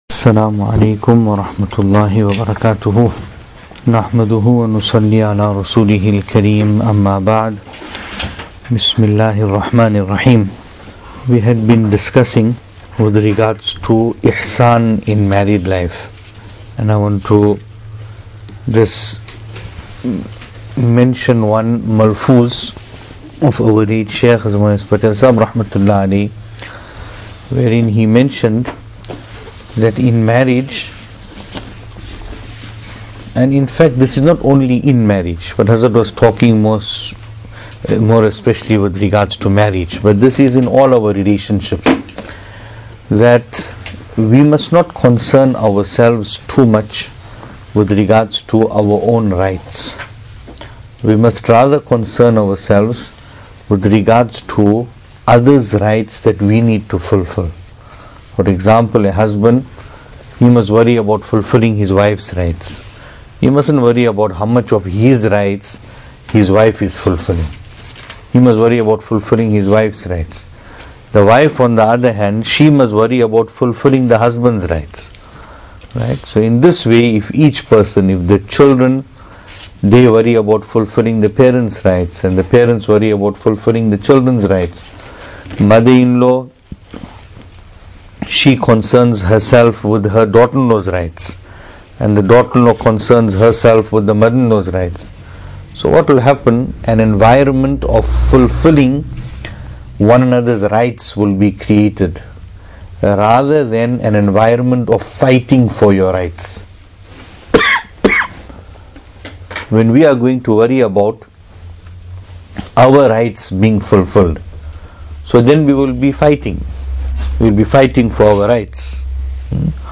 Venue: MADRESSA ISHA’ATUL HAQ, Pietermaritzburg | Series: Tohfa-e-Dulha